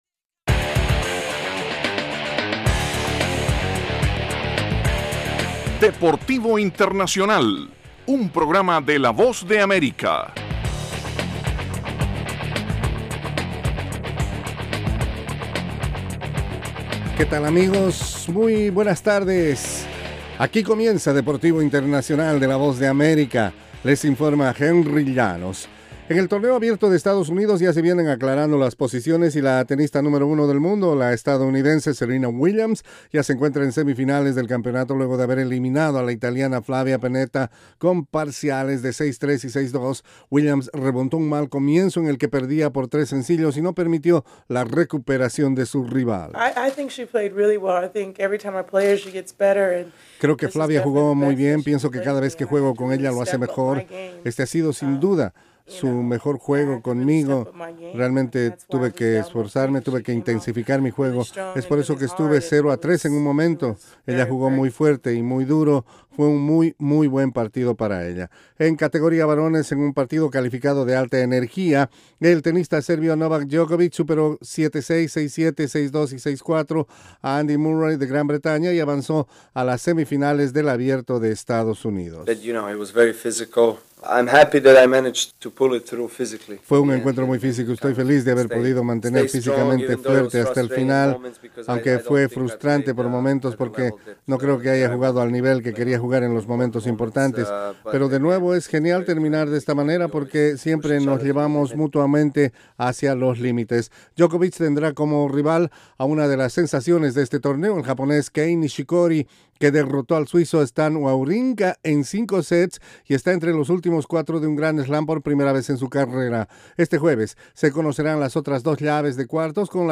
desde los estudios de la Voz de América